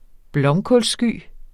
Udtale [ ˈblʌmkɔls- ]